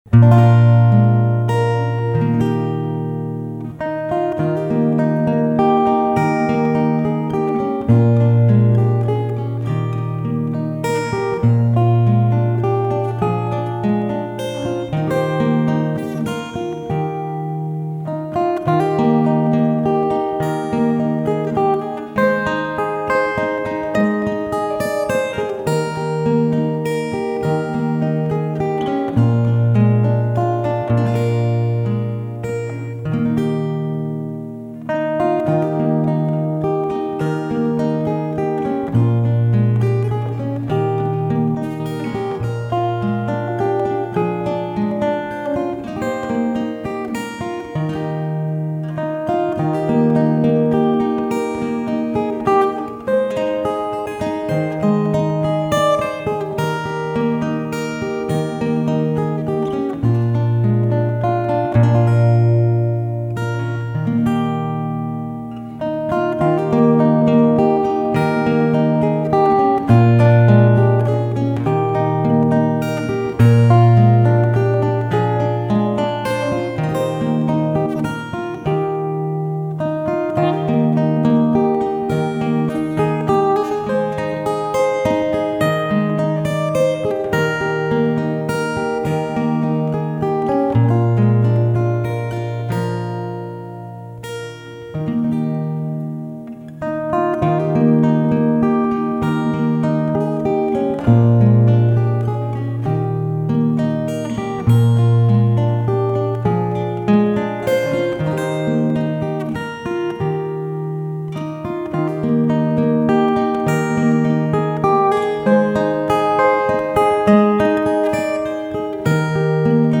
Vocals and music recorded live
Solo fingerstyle guitar.